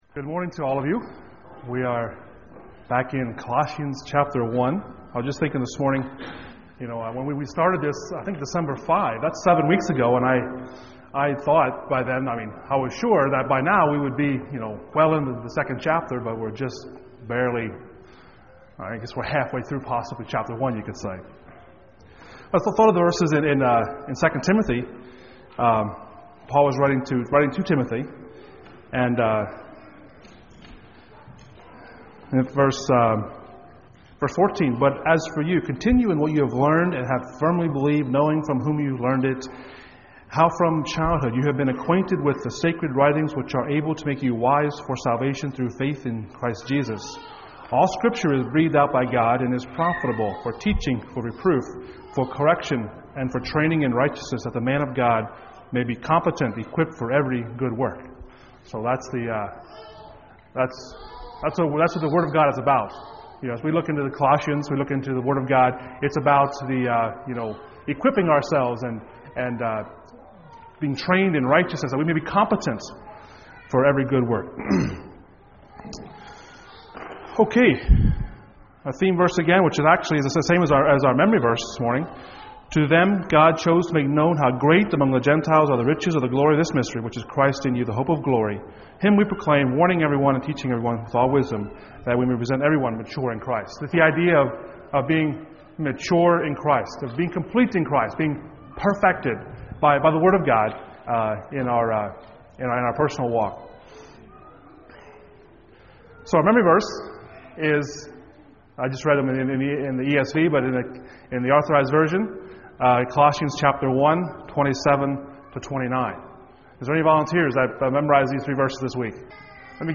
Passage: Colossians 1:9-14 Service Type: Sunday Morning